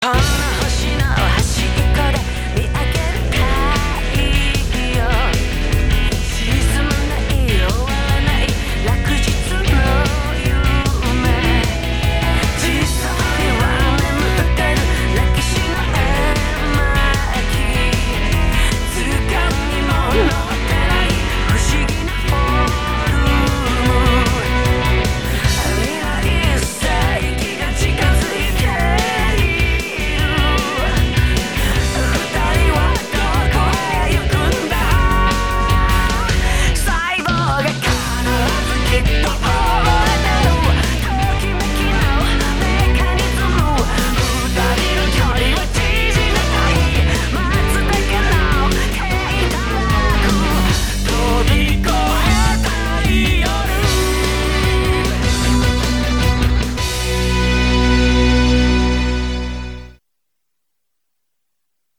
песня
нагло выдрана из одного непопулярного м/ф